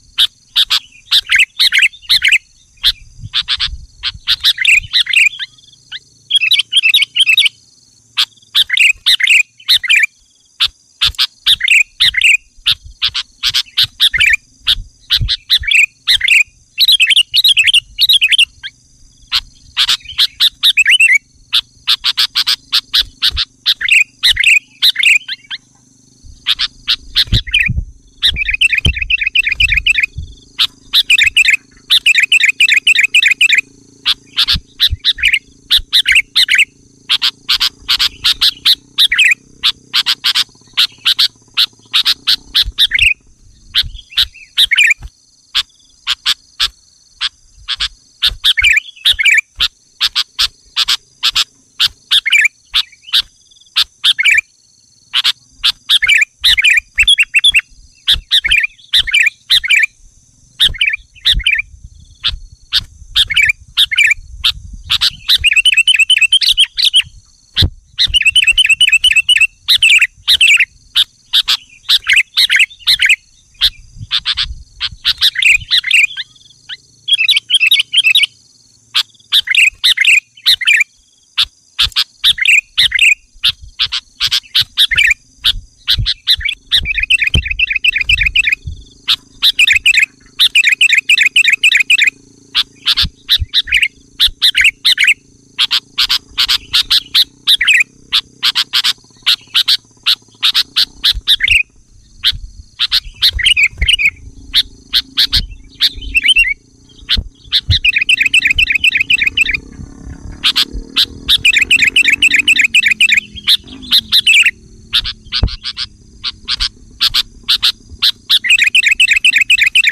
Suara Burung Kutilang Jernih
Tag: suara burung kecil suara burung Kutilang
suara-burung-kutilang-jernih-id-www_tiengdong_com.mp3